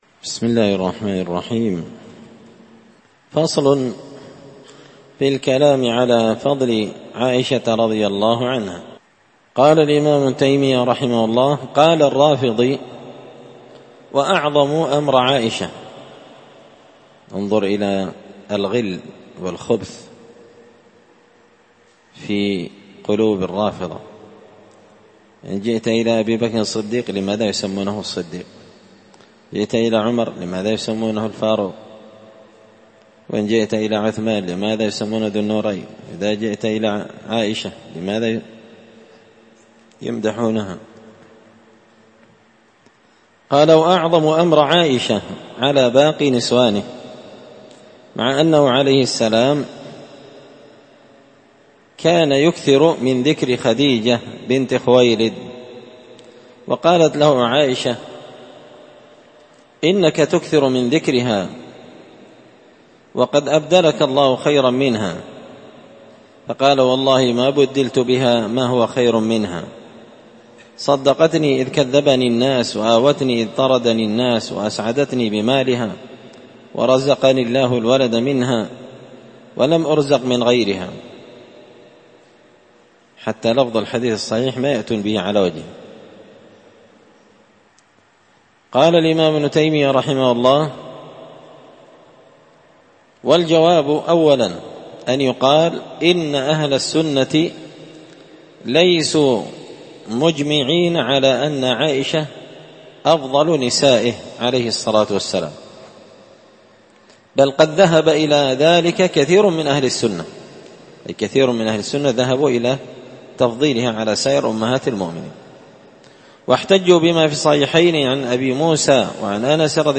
الأربعاء 13 شوال 1444 هــــ | الدروس، دروس الردود، مختصر منهاج السنة النبوية لشيخ الإسلام ابن تيمية | شارك بتعليقك | 41 المشاهدات